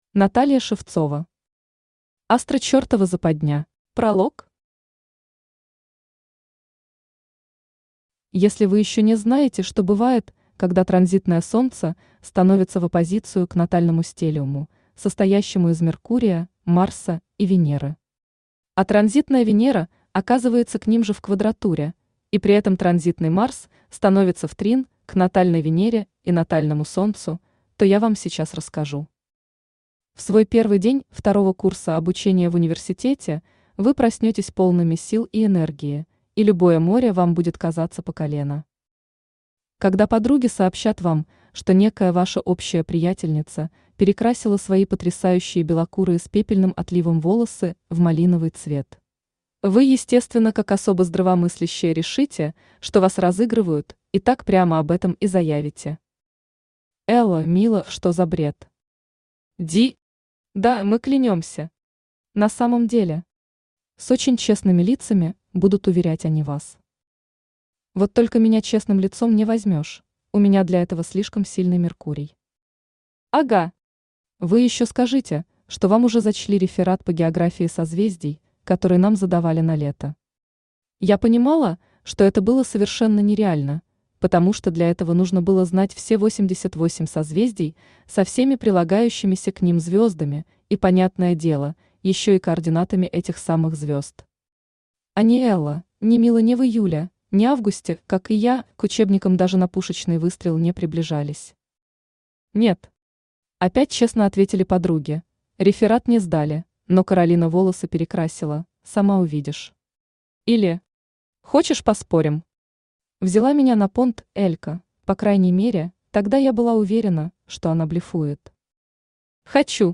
Аудиокнига Астро-Чертова Западня | Библиотека аудиокниг
Aудиокнига Астро-Чертова Западня Автор Наталья Шевцова Читает аудиокнигу Авточтец ЛитРес.